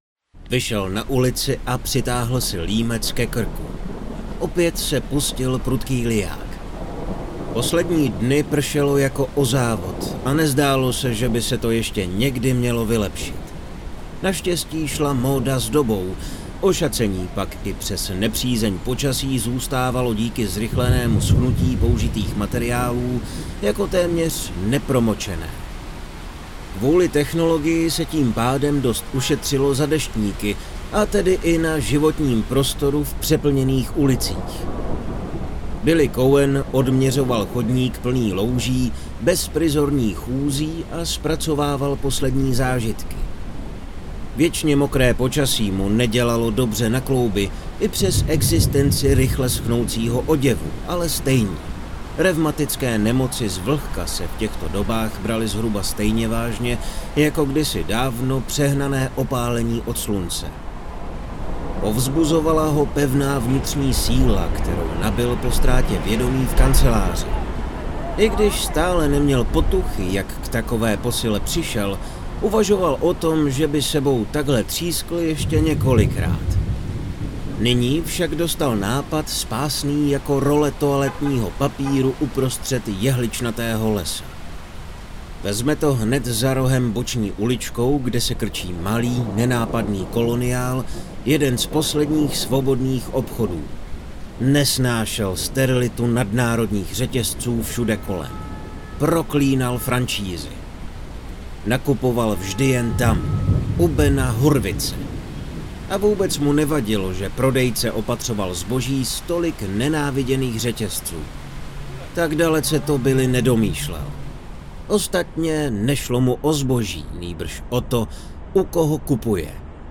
Kategorie: Sci-fi